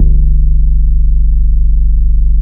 BASS 5.wav